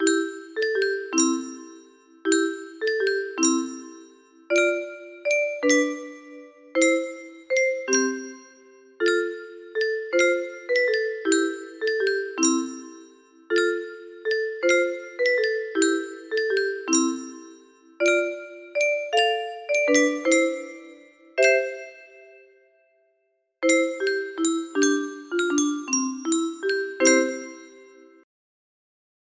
고요한밤 거룩한밤 music box melody